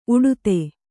♪ uḍte